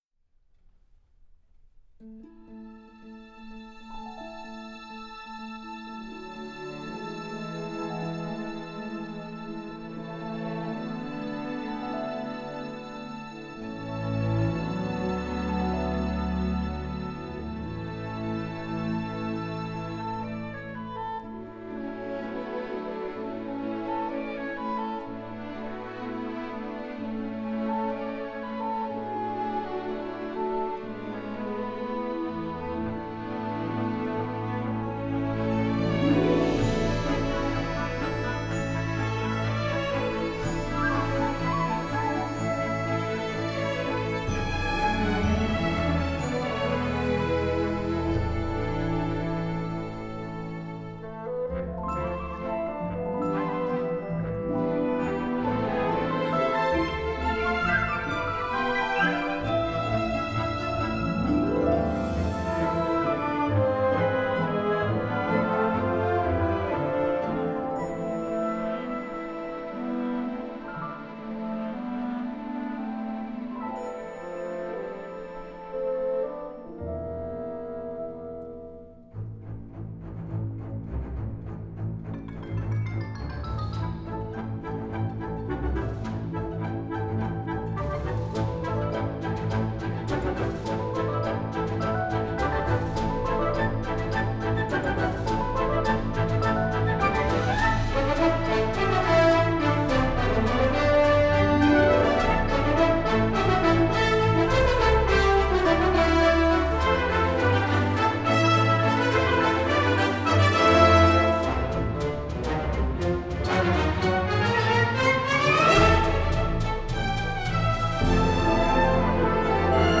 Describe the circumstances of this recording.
Recorded at Eastwood Scoring Stage (Burbank, CA)